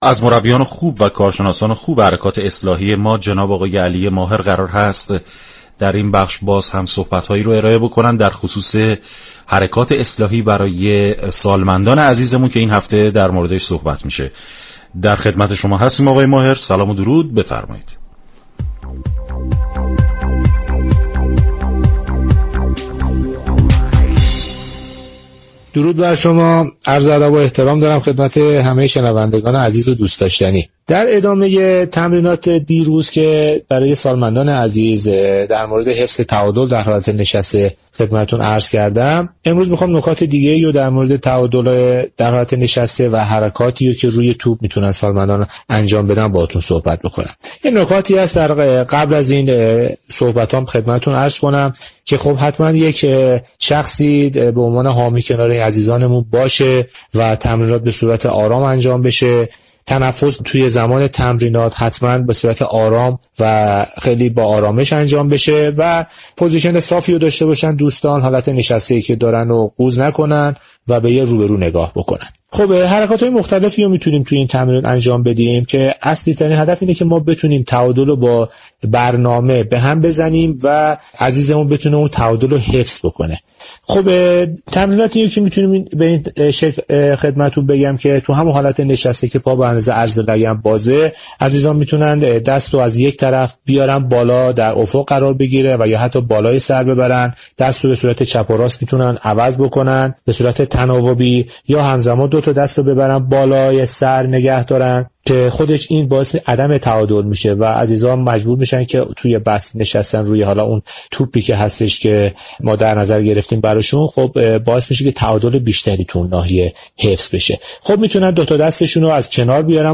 شما می توانید از طریق فایل صوتی پیوست شنونده صحبت های كامل این متخصص حركات اصلاحی باشید. برنامه "گلخونه" با محوریت ترویج سبك زندگی اسلامی ایرانی با هدف ایجاد و تقویت انگیزه برای بهبود وضعیت سواد حركتی در نهاد خانواده ساعت 10 هر روز به مدت 60 دقیقه از شبكه رادیویی ورزش تقدیم شنوندگان می شود.